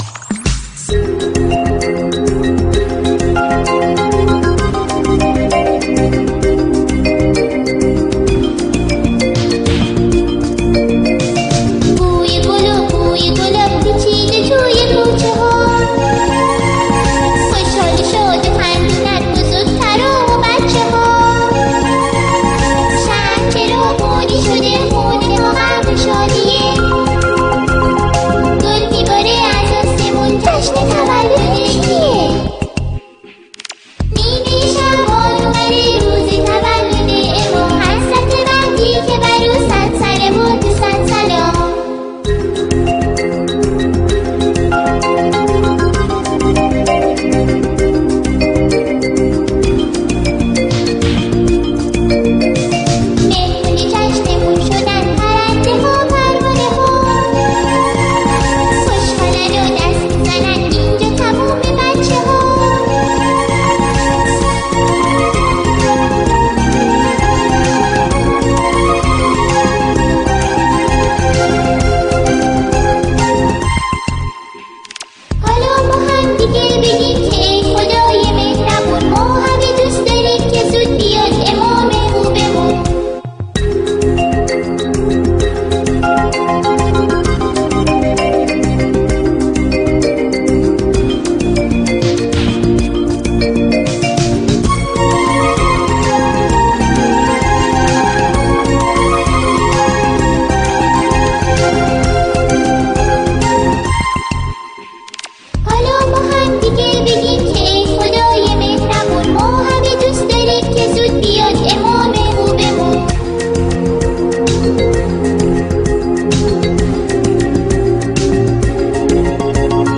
مولودی نیمه شعبان کودکانه
دانلود شعر صوتی کودکانه نیمه شعبان